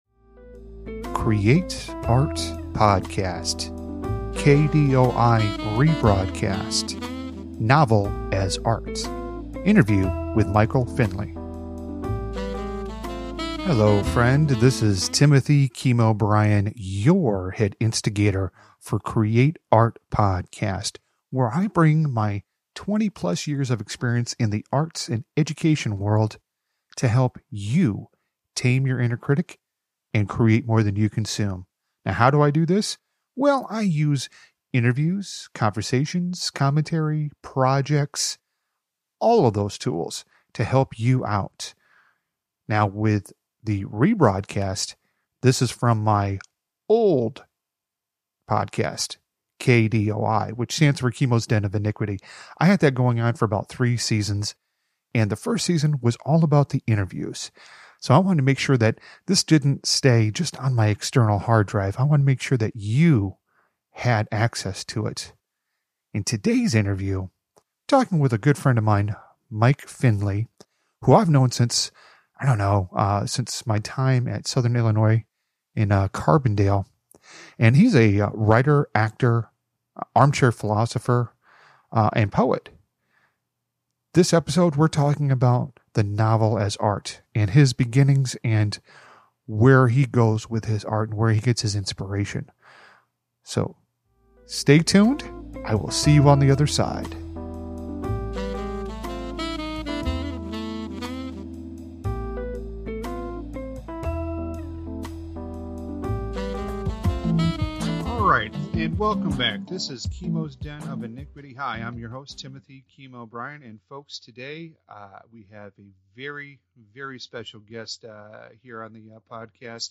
KDOI Rebroadcast of interview